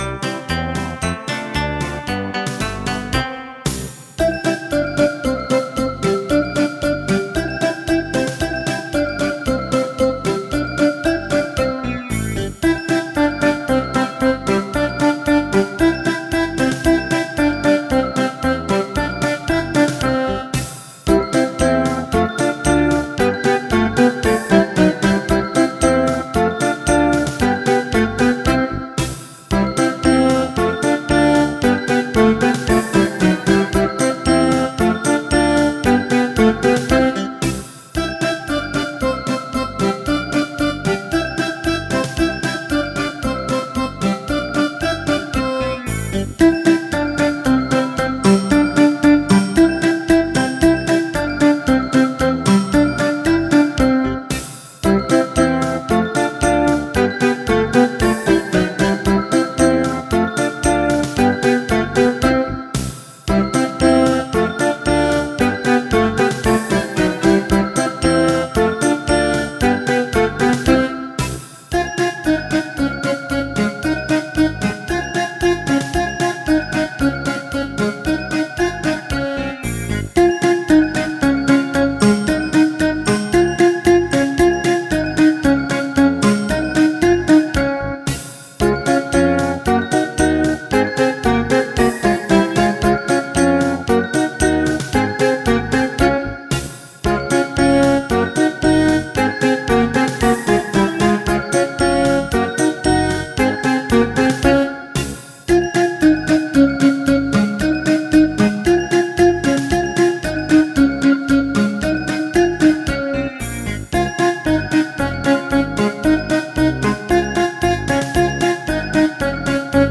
5) Podkłady muzyczne do Przeglądu Tańca Tradycyjnego: wiwat,
polka
polka.mp3